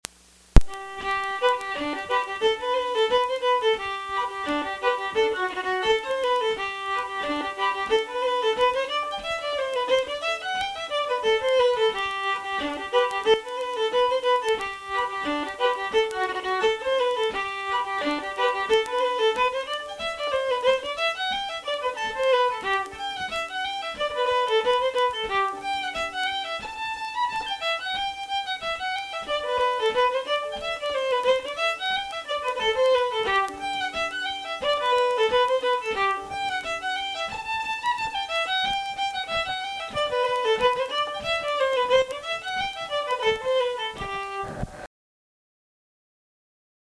Fiddle MP3